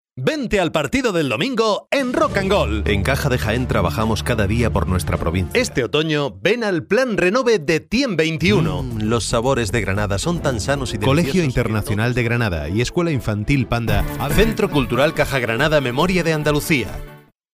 Adulto joven, adulto +40, español neutro, español andaluz, Publicidad, Documental, E-learning, Dibujos animados, Jingles, Canciones, Doblaje, Juegos, Presentaciones, Podcasts/internet, Sistema de teléfono, Moderación (on), Audiolibros, estudio propio.
Sprechprobe: Werbung (Muttersprache):
Type of voice: Spanish. Tenor, young adult, Adult +40.